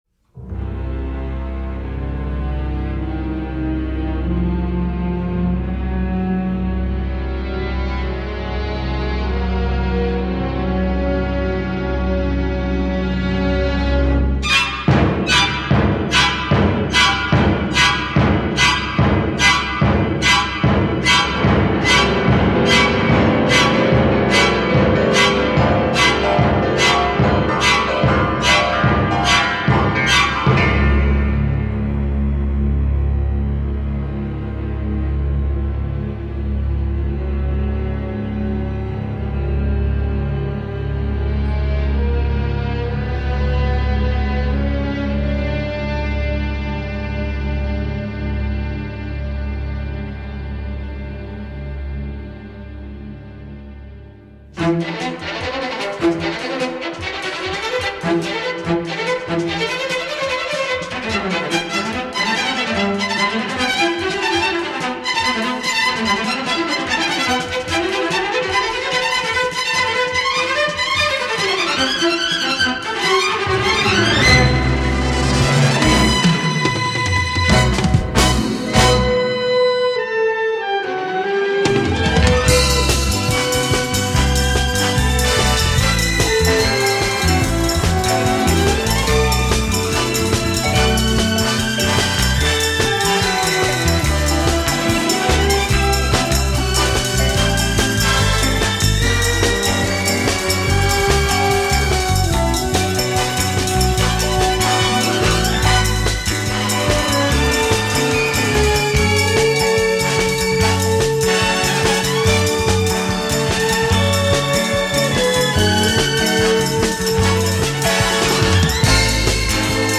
souvent tendue et grinçante dans sa première moitié